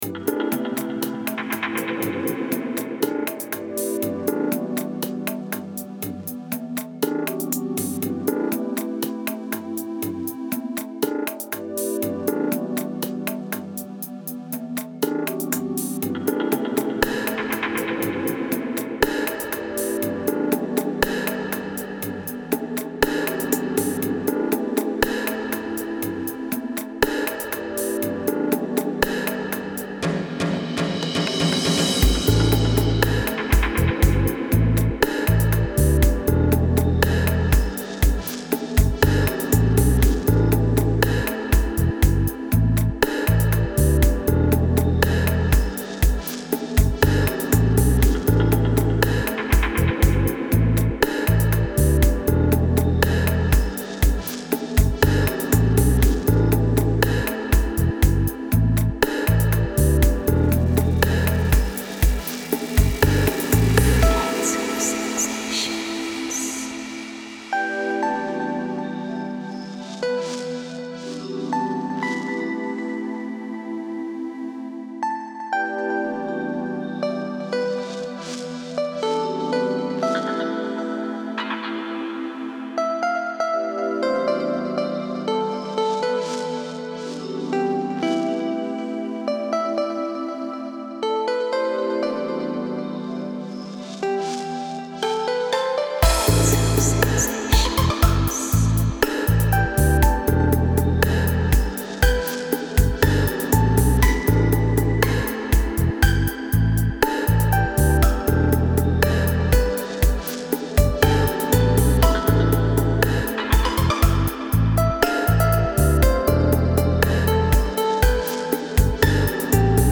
Genre: Chill Out.